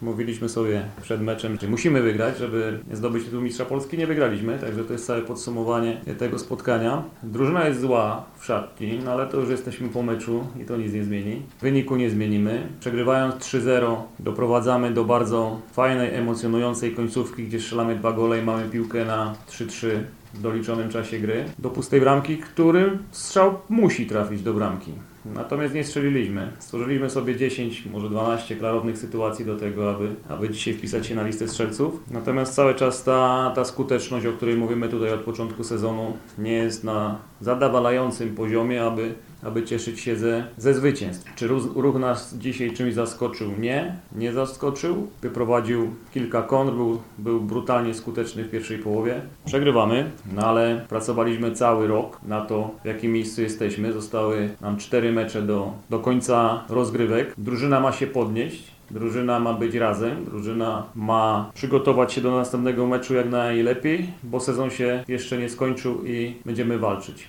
– Drużyna przegrała mecz i ja też przegrałem jako trener – powiedział z kolei Jacek Magiera, szkoleniowiec Śląska.